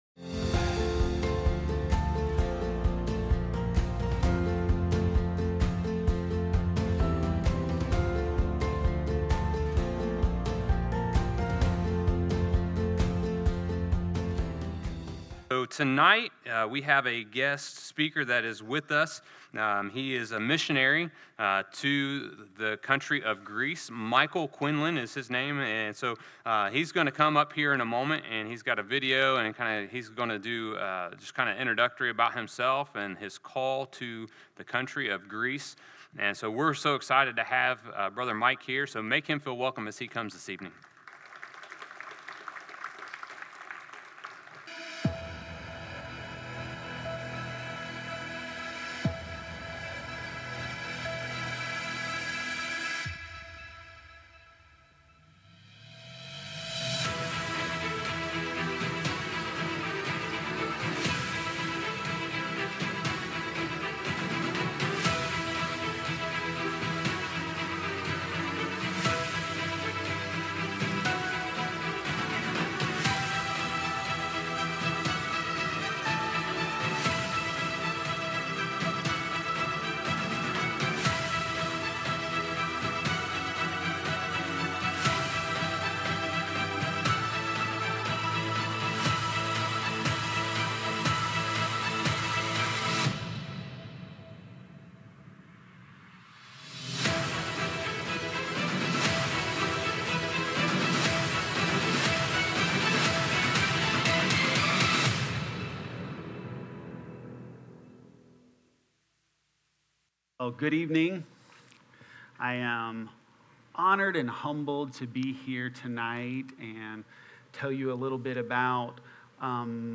Missionary Presentation